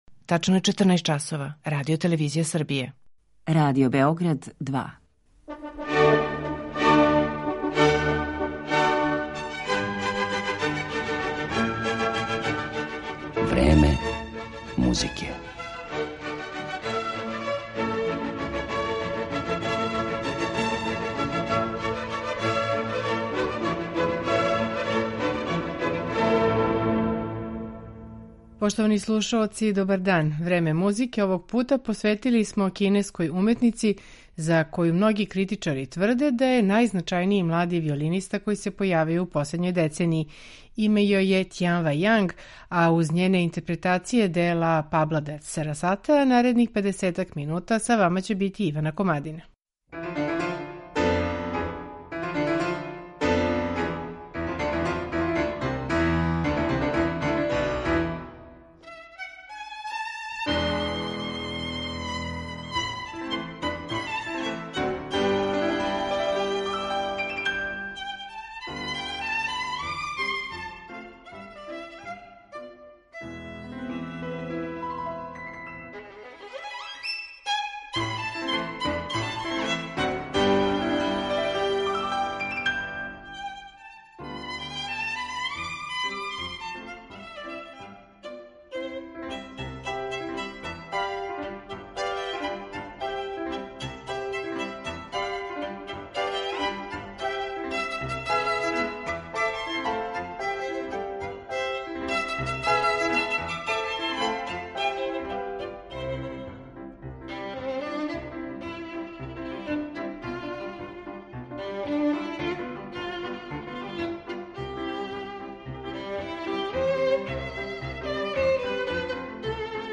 композиција за виолину и клавир и виолину и оркестар